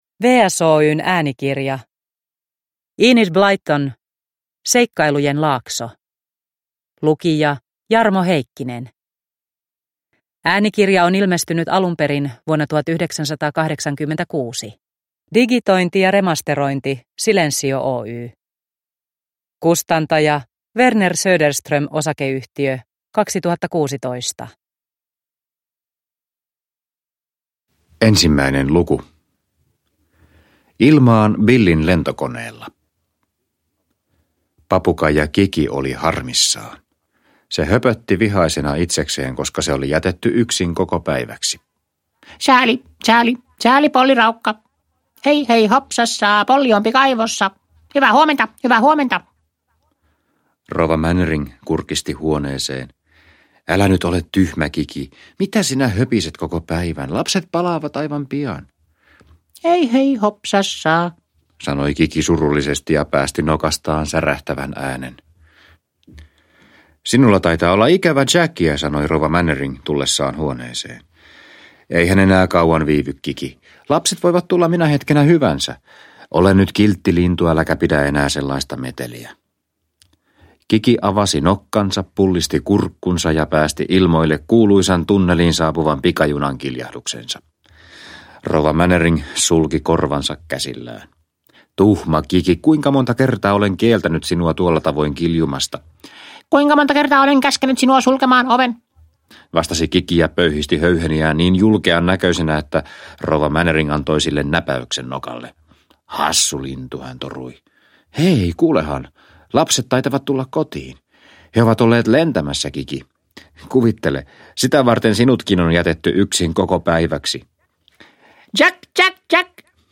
Seikkailujen laakso – Ljudbok – Laddas ner